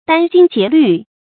殚精竭虑 dān jīng jié lǜ 成语解释 用尽精力；费尽心思（殚；竭：用尽；虑：心思）。
成语繁体 殫精竭慮 成语简拼 djjl 成语注音 ㄉㄢ ㄐㄧㄥ ㄐㄧㄝ ˊ ㄌㄩˋ 常用程度 常用成语 感情色彩 中性成语 成语用法 联合式；作谓语、状语；多用于书面语 成语结构 联合式成语 产生年代 古代成语 成语正音 殚，不能读作子弹的“dàn”。